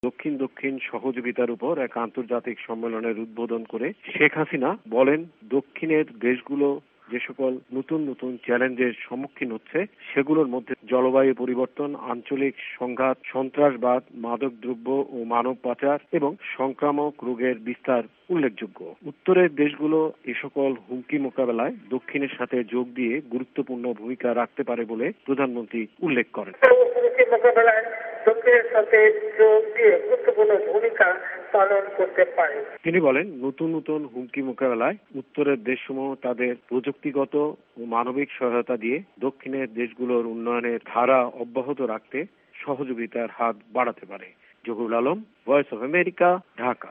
আজ রবিবার ঢাকায় দক্ষিন দক্ষিন সহযোগিতার ওপর আয়োজিত এক আন্তর্জাতিক সম্মেলনের উদ্বোধন করে বাংলাদেশের প্রধানমন্ত্রী শেখ হাসিনা বলেছেন দক্ষিনের দেশগুলো যখন অর্থনৈতিক ও সামাজিক খাতে অগ্রগতি অর্জন করছে ঠিক তখুনি দক্ষিনের দেশগুলোর স্থিতিশীলতার প্রতি হুমকি হয়ে দাঁড়াচ্ছে নতুন নতুন চ্যালেঞ্জ। এরই ওপর ঢাকা থেকে রিপোর্ট পাঠিয়েছেন